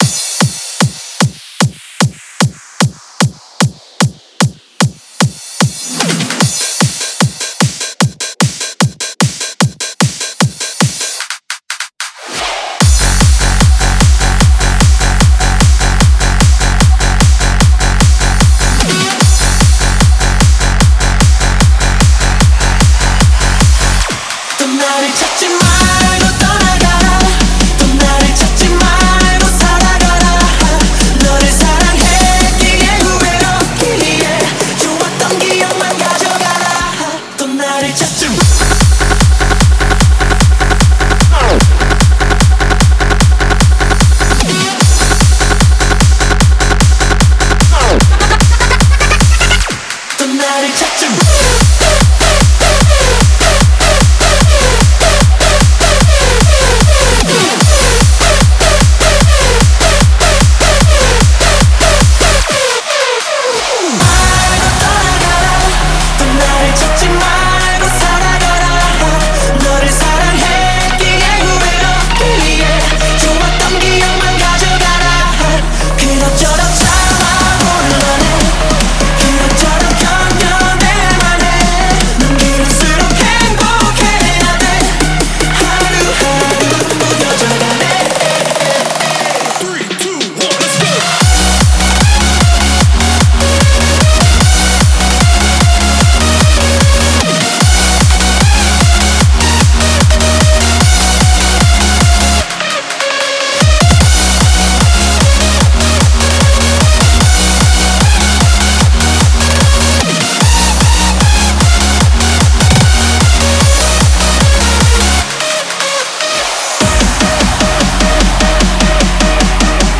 试听文件为低音质，下载后为无水印高音质文件 M币 6 超级会员 M币 3 购买下载 您当前未登录！